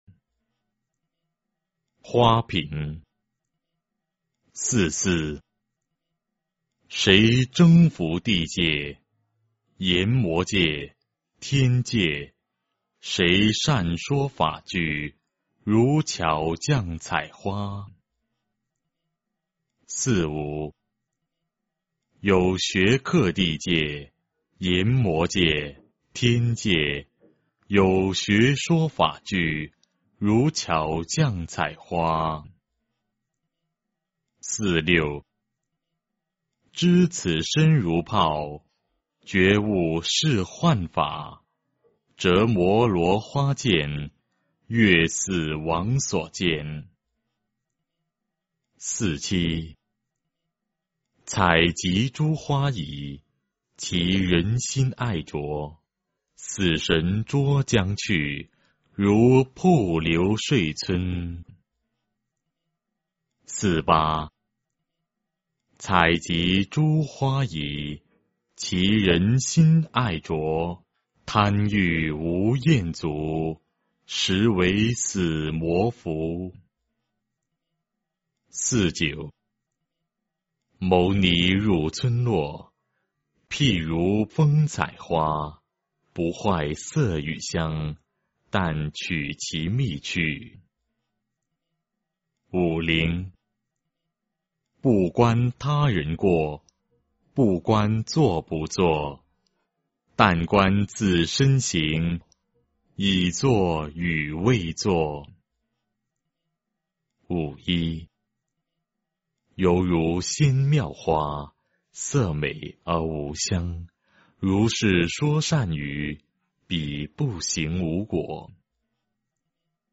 法句经-花品（念诵）